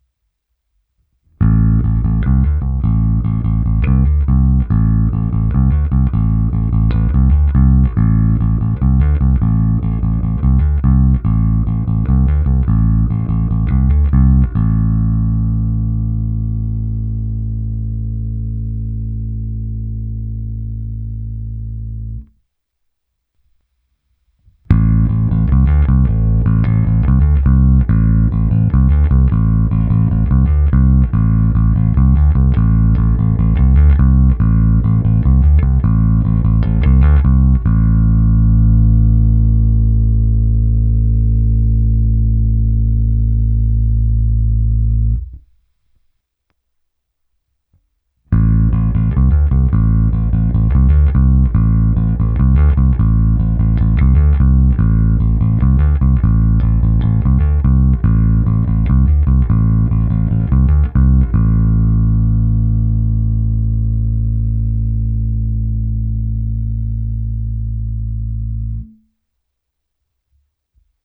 Kompresor opravdu funguje skvěle, nežere basy, a když se to nepřežene s výstupní hlasitostí, tak ani nešumí.
V následující nahrávce s baskytarou Fender American Professional II Precision Bass V jsou tři části. Hra bez kompresoru, hra s kompresorem Ampeg Opto Comp a v třetí části pro srovnání hra s kompresorem TC Electronic SpectraComp.
Ukázka prsty